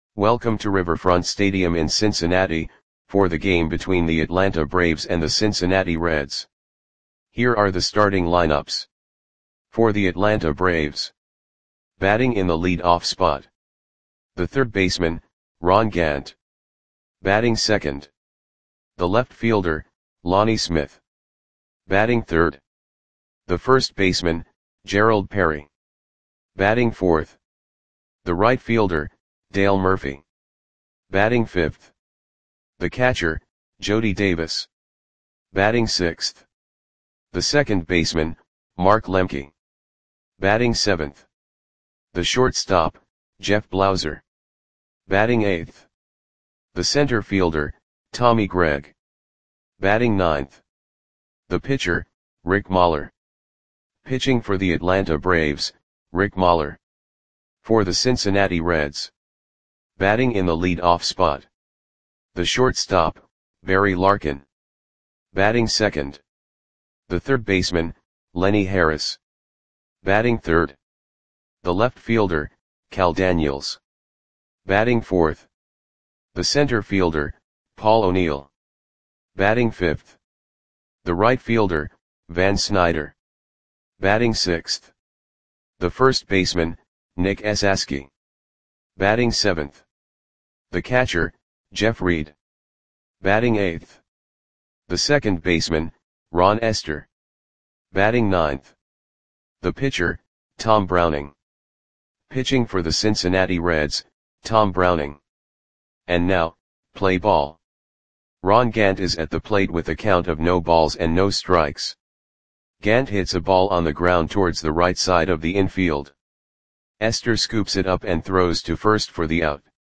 Audio Play-by-Play for Cincinnati Reds on September 30, 1988
Click the button below to listen to the audio play-by-play.